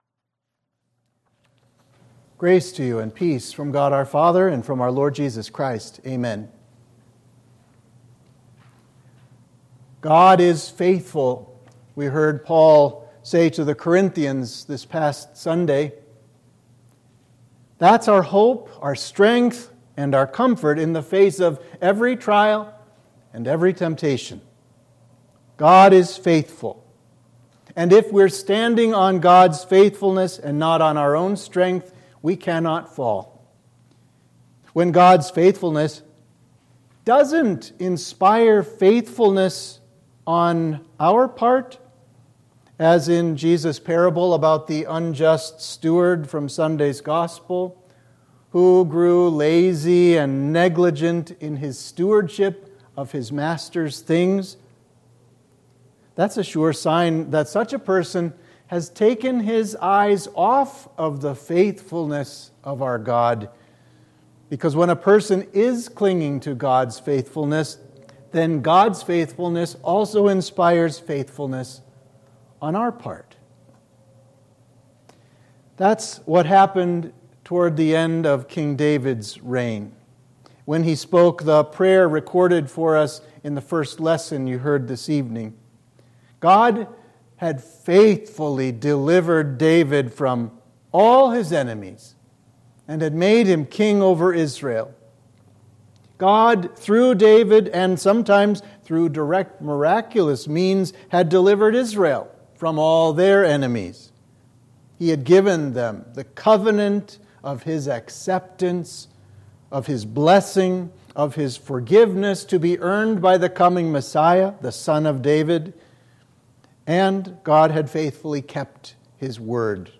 Sermon for midweek of Trinity 9